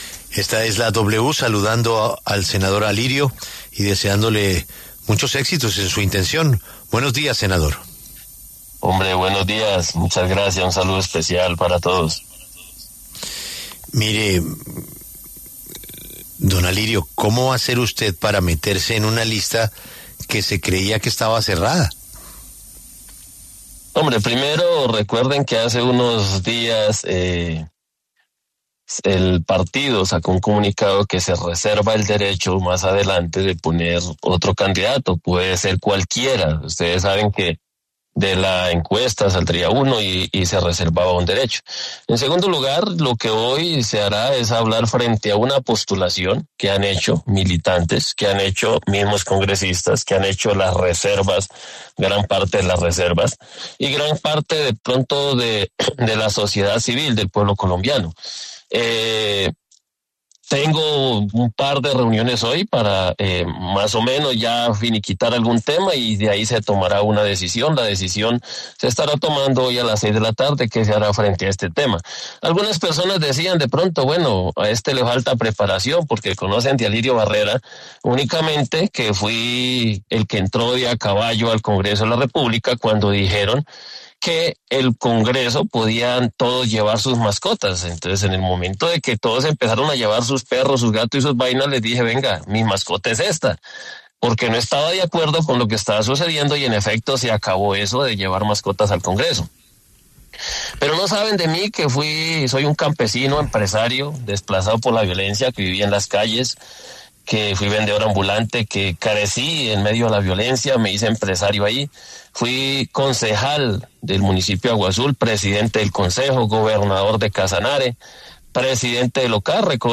El senador Alirio Barrera, del Centro Democrático, pasó por los micrófonos de La W y habló sobre su eventual aspiración presidencial.